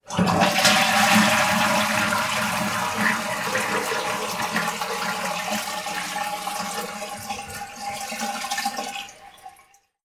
toilet_flush.wav